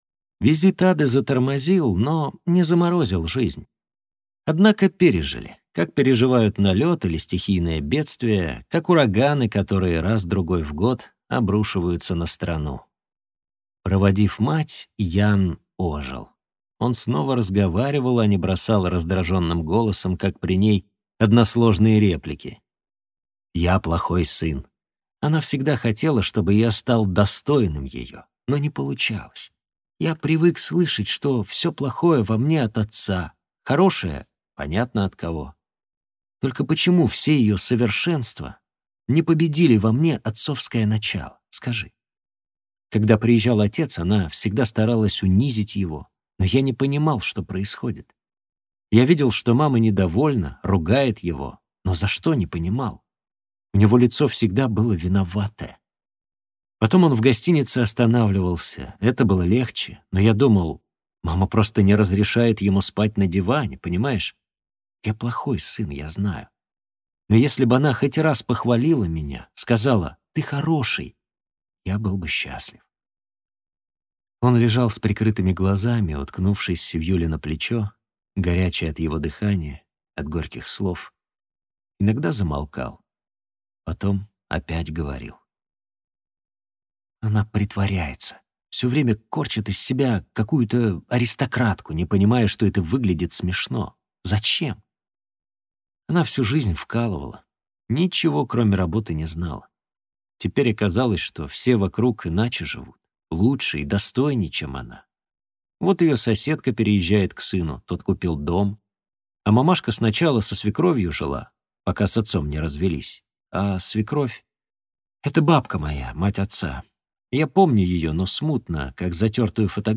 Аудиокнига Джек, который построил дом | Библиотека аудиокниг